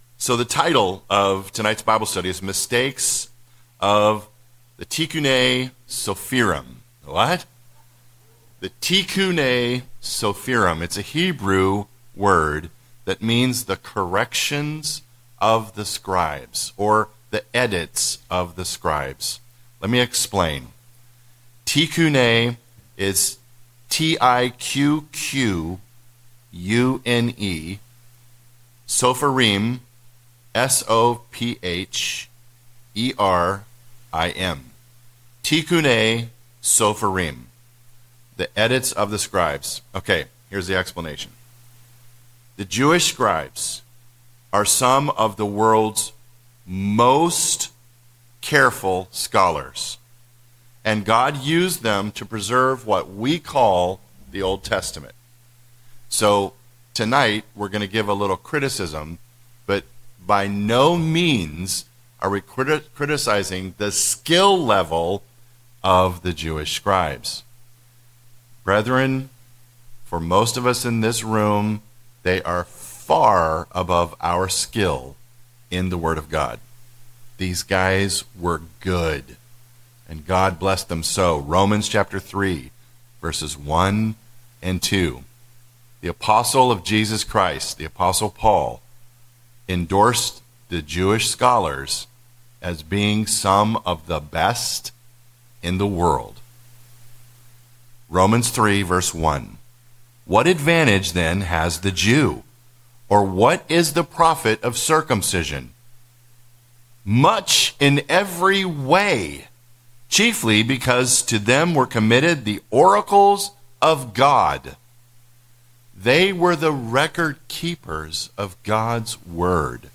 The phrase Tiqqune Sopherim refers to 18 different scriptural edits that some Jewish scholars made, falsely perceiving they were necessary. This Bible study reviews a few of these edits and delves into the motivation behind them and how we, as Christians, tend to make the same mistakes even today.